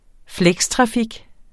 Udtale [ ˈflεgs- ]